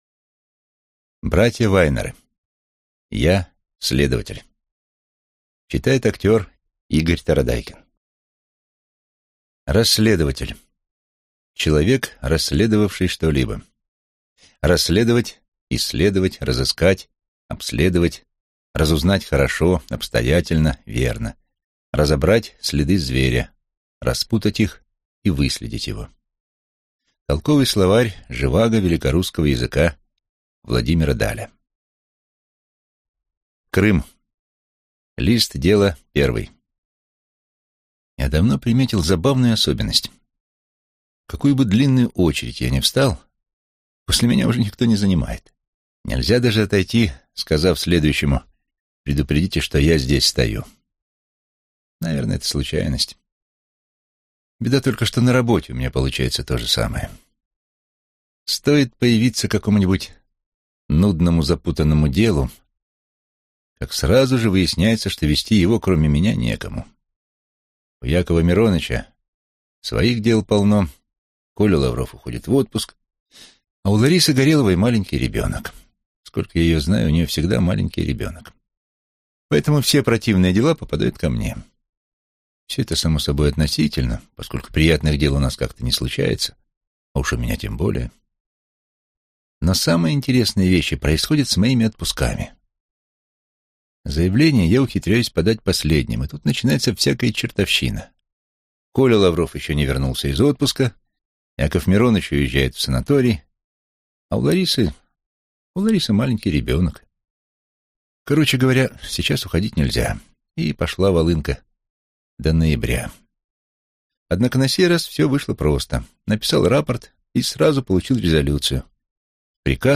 Аудиокнига Я, следователь…